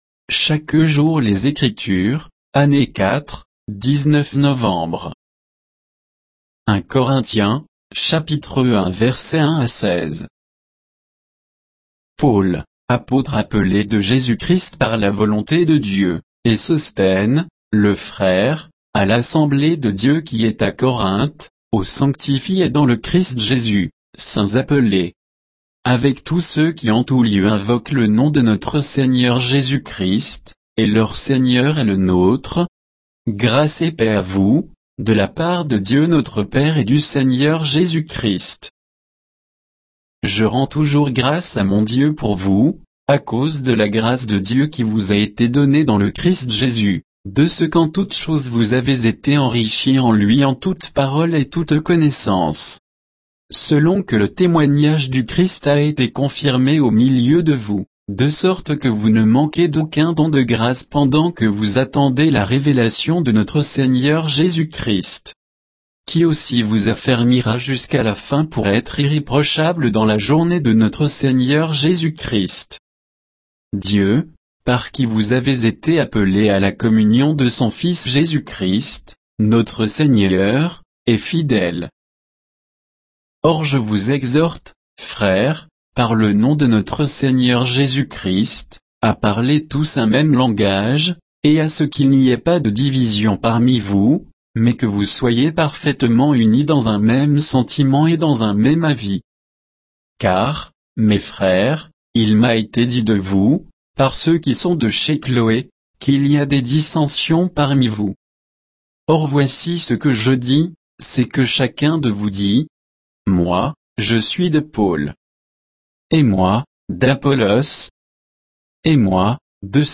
Méditation quoditienne de Chaque jour les Écritures sur 1 Corinthiens 1